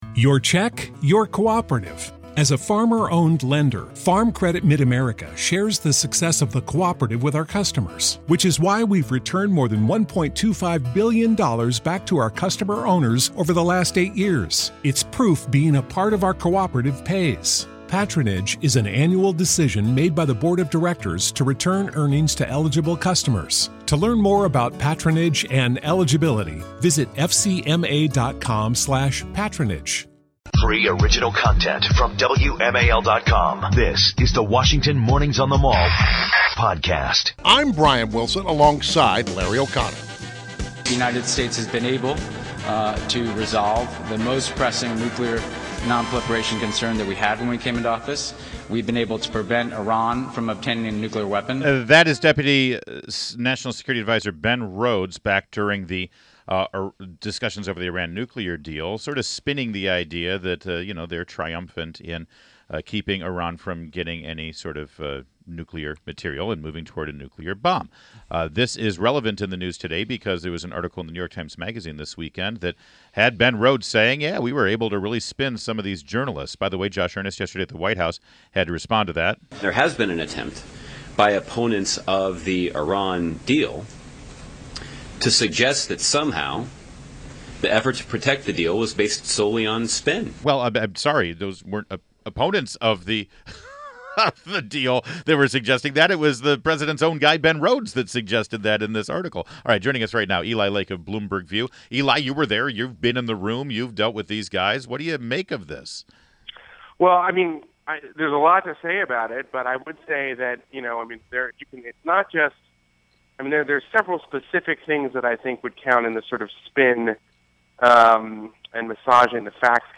INTERVIEW - ELI LAKE - Bloomberg View Columnist